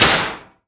metal_impact_light_thud_04.wav